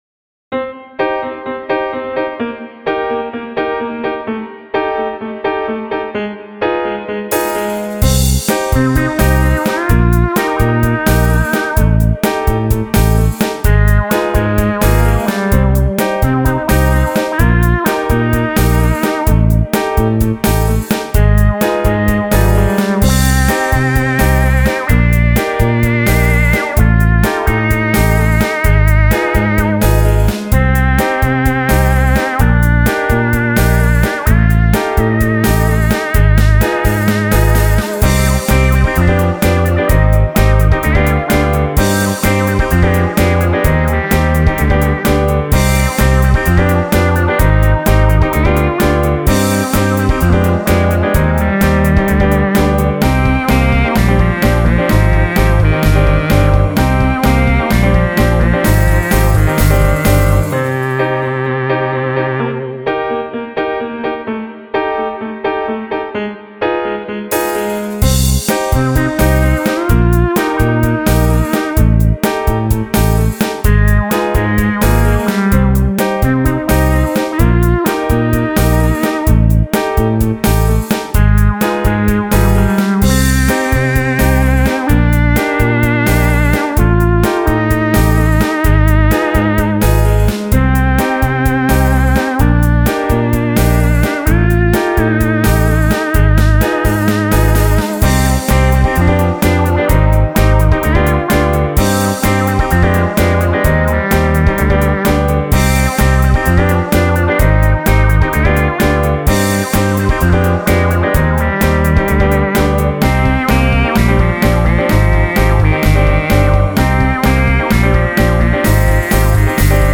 spanish dance, which is often in 6/8 time.